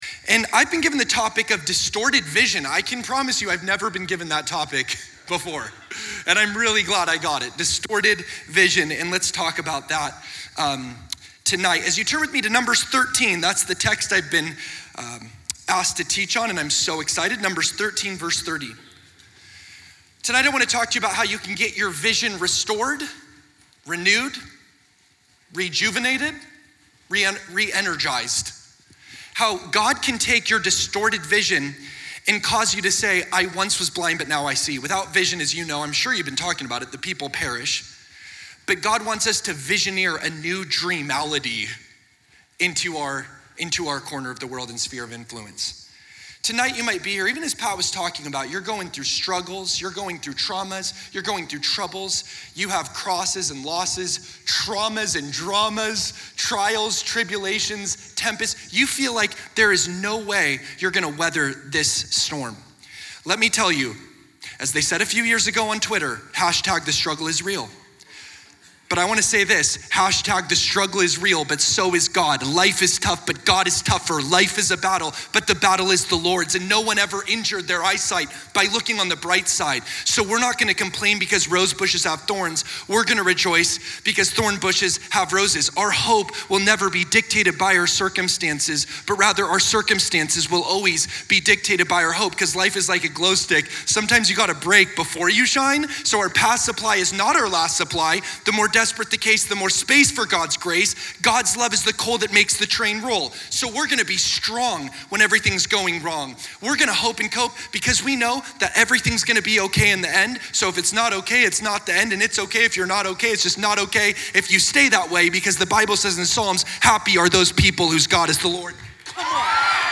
2020 Southwest Pastors and Leaders Conference